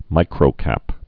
(mīkrō-kăp)